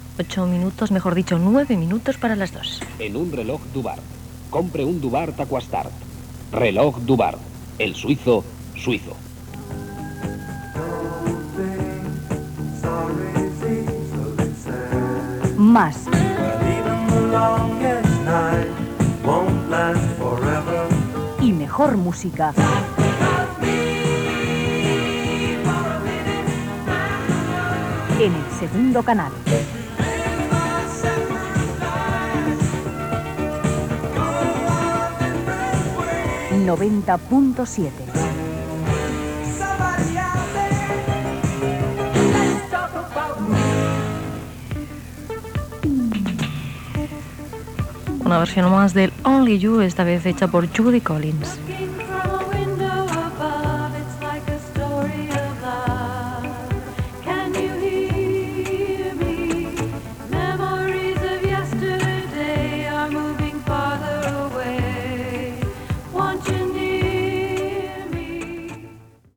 Hora, publicitat, indicatiu, tema musical.
FM